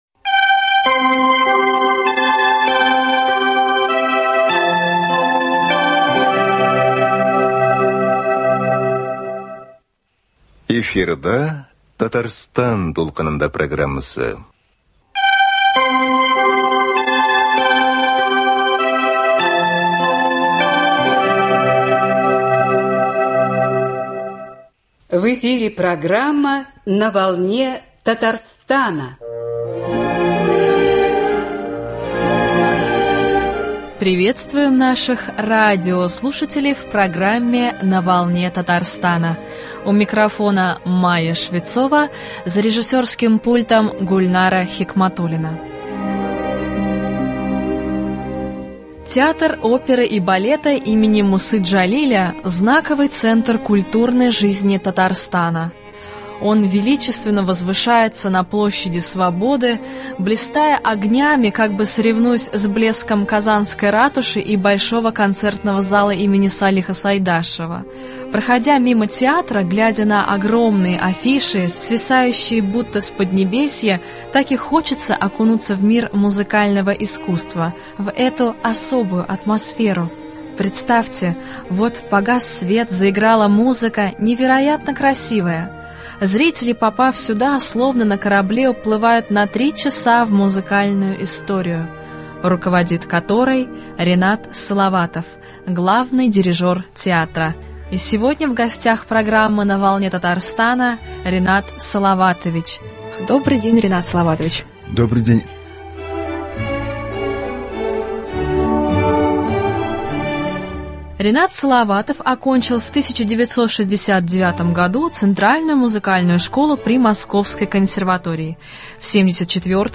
В программе звучат сочинения